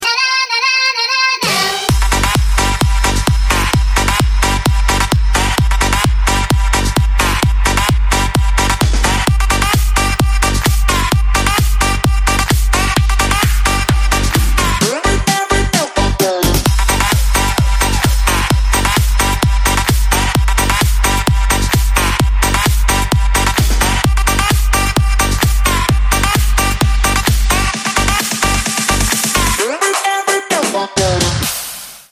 • Качество: 320, Stereo
громкие
заводные
Electronic
EDM
club
progressive house
быстрые
bounce
Заводной баунс!